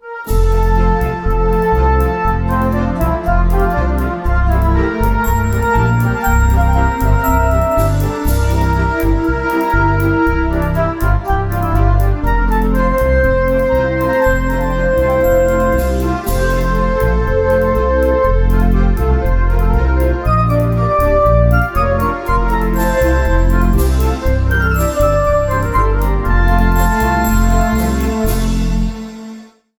Pasodoble.
pasodoble
melodía
Sonidos: Música